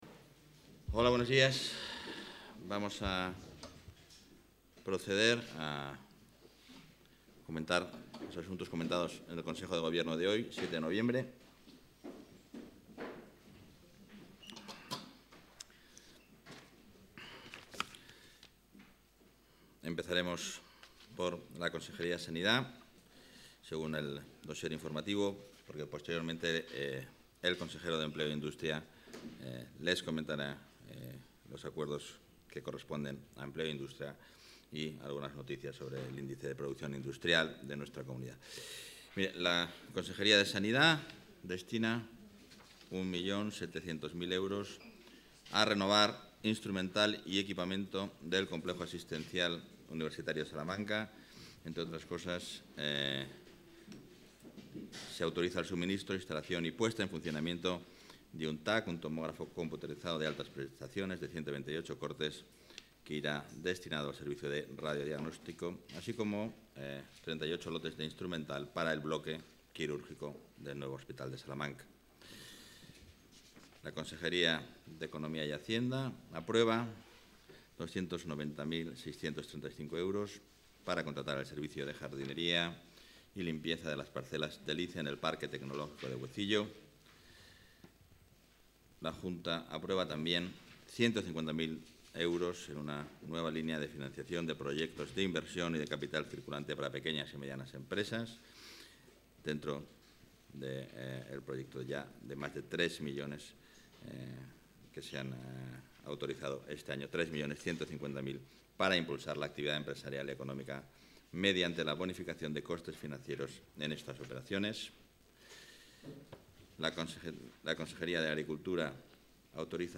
Rueda de prensa tras Consejo de Gobierno.